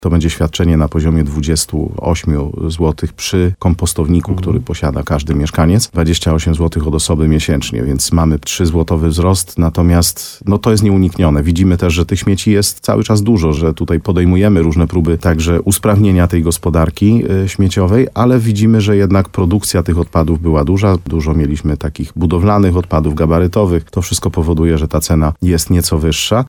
Jak mówił w programie Słowo za Słowo w radiu RDN Nowy Sącz wójt Mariusz Tarsa, wzrost kosztów o kilka złotych to obecnie konieczność.